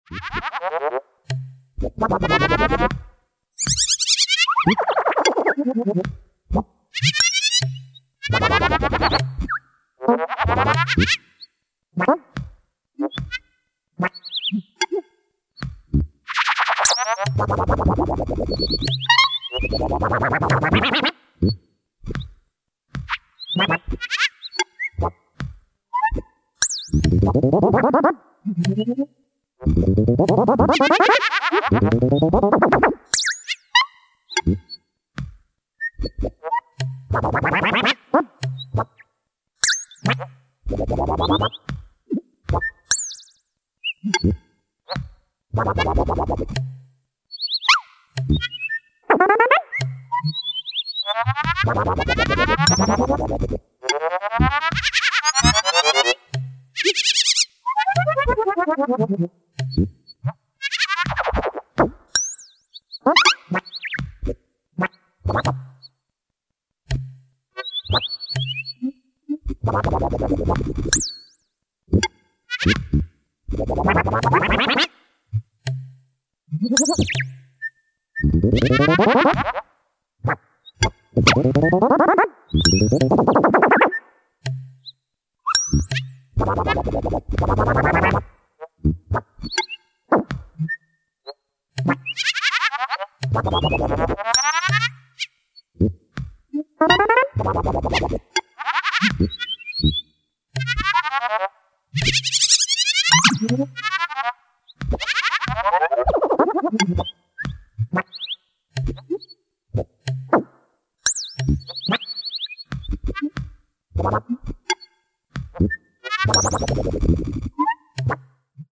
the maverick master of British Experimental Music.